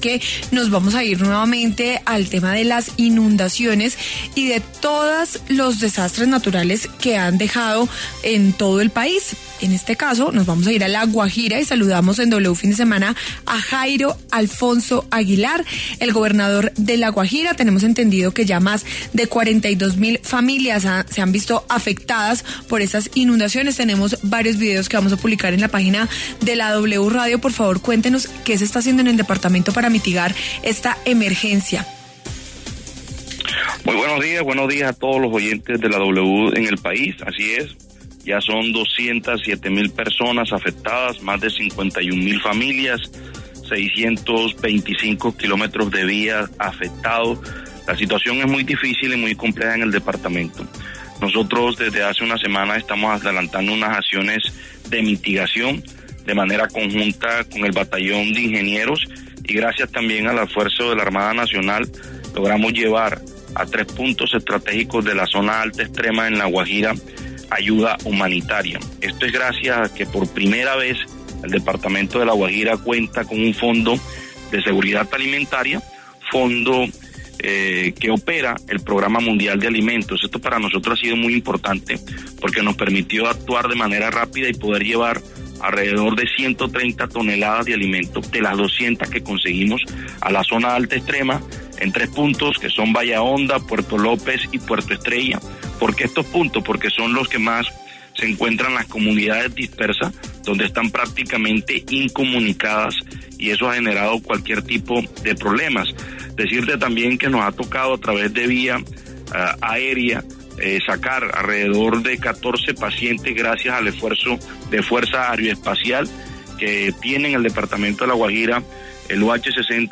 Jairo Aguilar, gobernador de La Guajira, pasó por los micrófonos de W Fin de Semana afirmando que ya son más de 207 mil personas afectadas en el departamento.